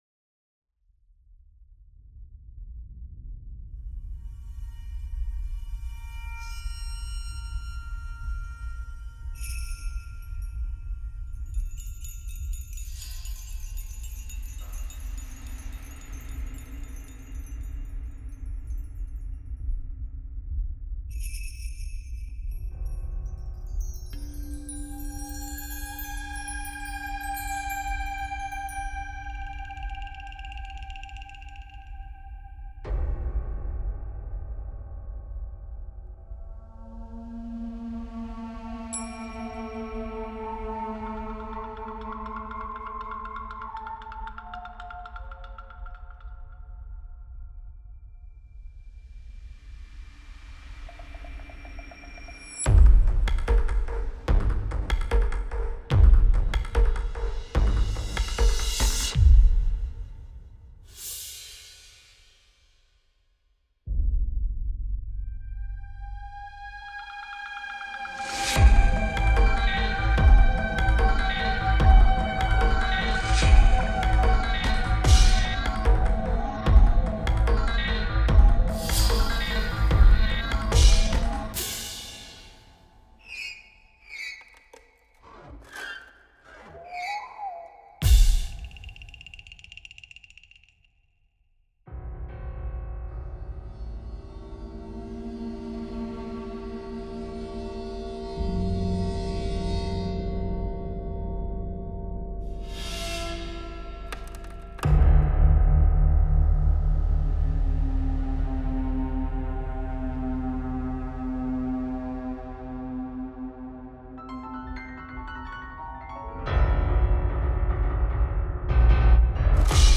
Composition pour les ténèbres blanches: Un piano, quatre synthétiseurs et sept pistes d’objets sonores et musicaux…